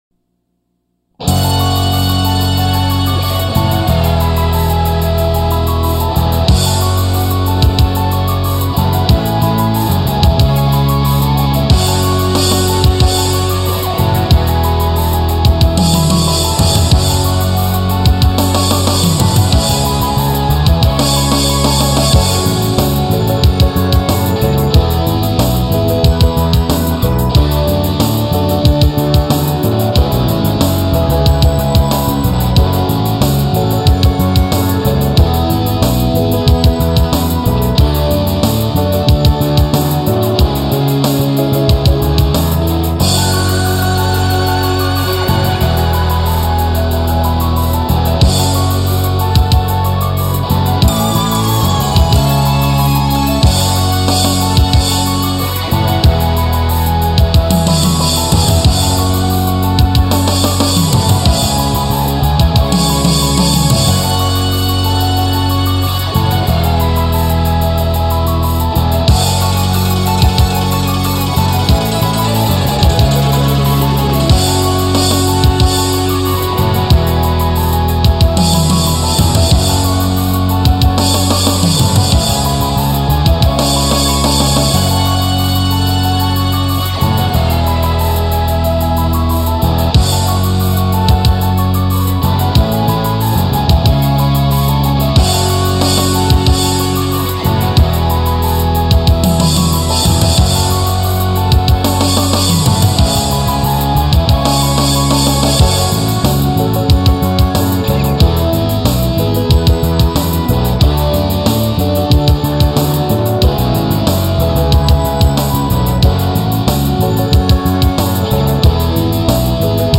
With No Lead Guitars Full Band Music Track